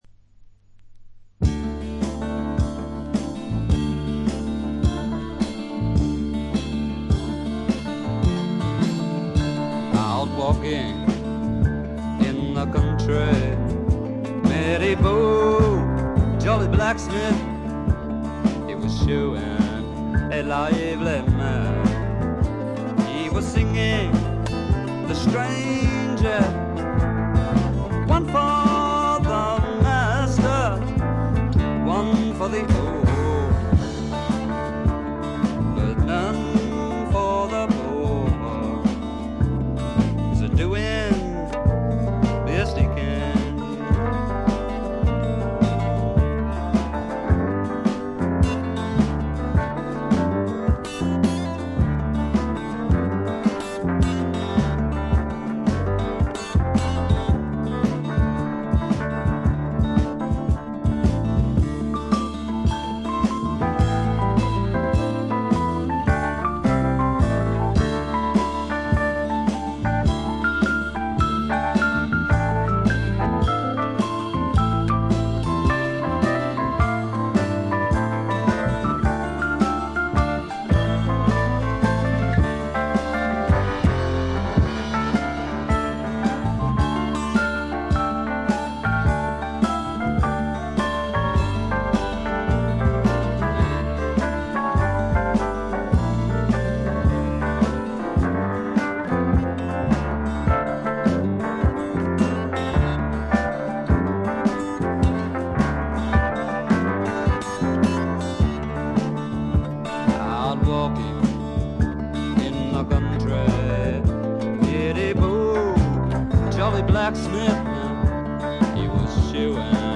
チリプチがそこそこ。散発的なプツ音も少し。
試聴曲は現品からの取り込み音源です。